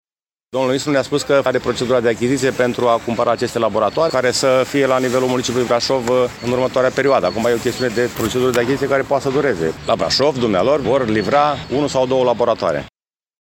Primarul Braşovului, George Scripcaru: